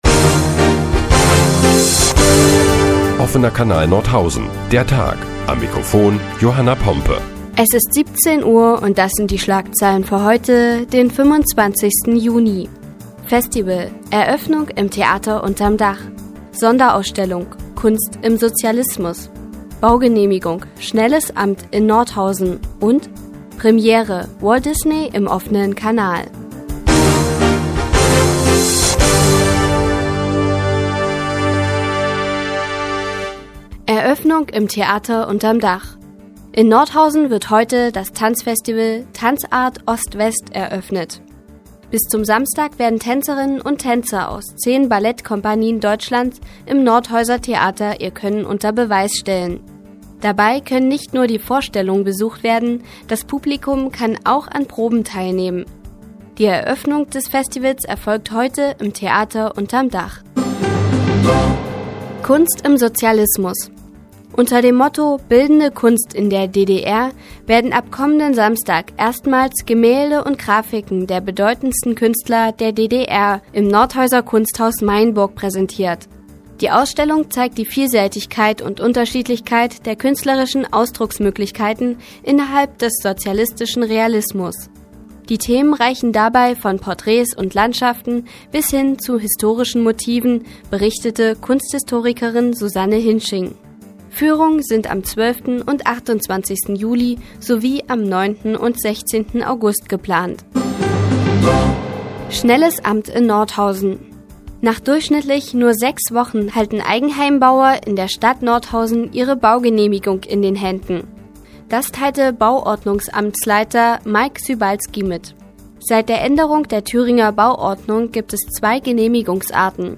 Die tägliche Nachrichtensendung des OKN ist nun auch in der nnz zu hören. Heute geht es unter anderem um ein Festival im Theater und eine Ausstellung im Kunsthaus Meyenburg.